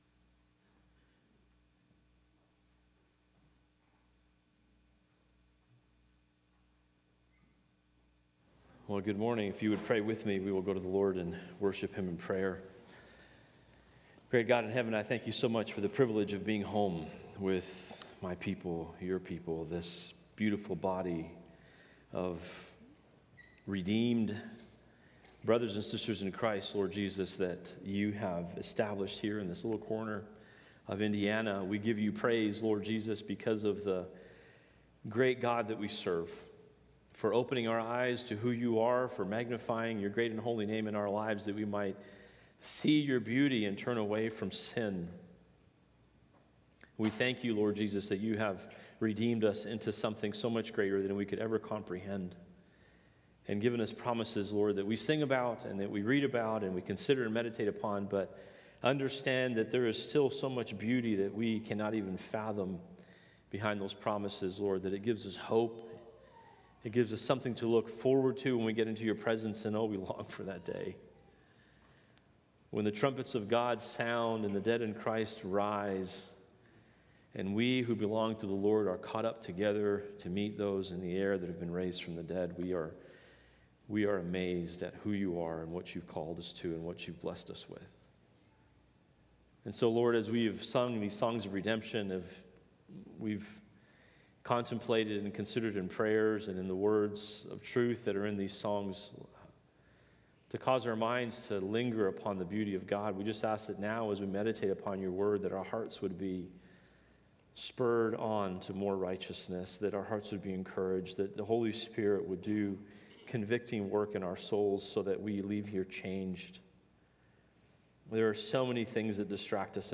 A message from the series "1 Corinthians."